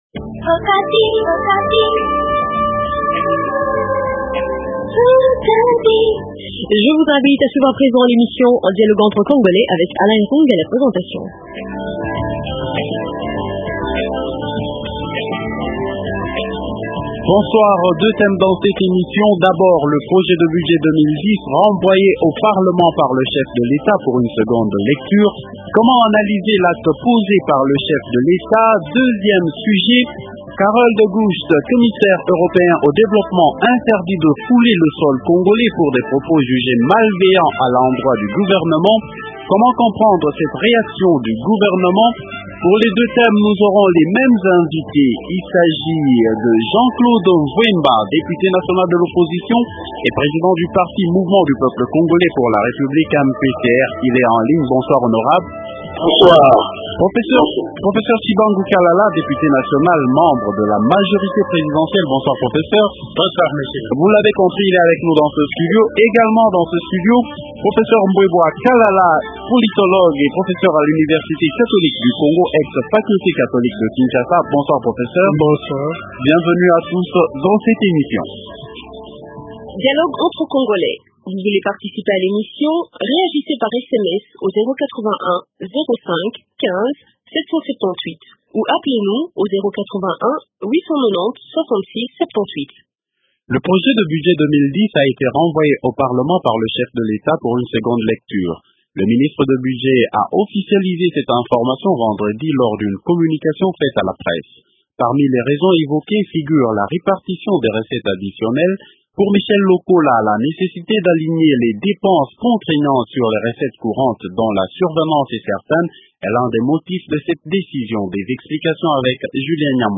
Deux thèmes dans l’émission Dialogue entre congolais de ce soir :